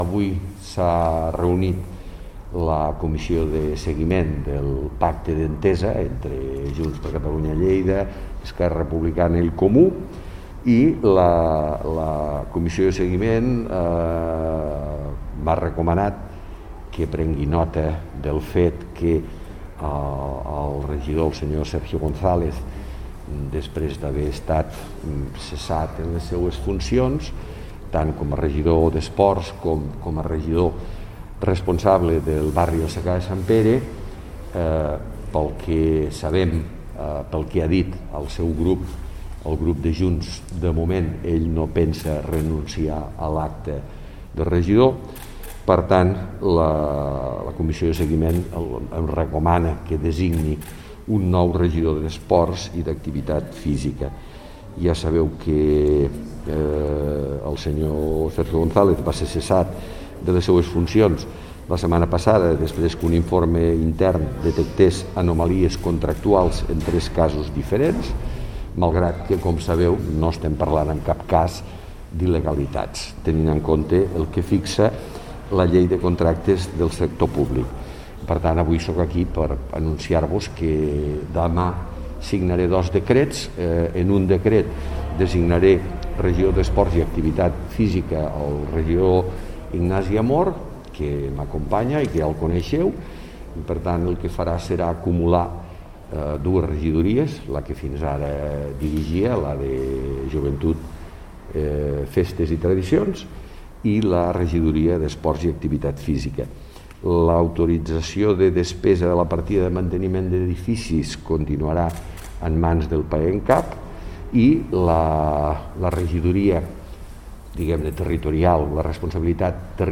Compartir Facebook Twitter Whatsapp Descarregar ODT Imprimir Tornar a notícies Fitxers relacionats Tall de veu del paer en cap, Miquel Pueyo (3.4 MB) T'ha estat útil aquesta pàgina?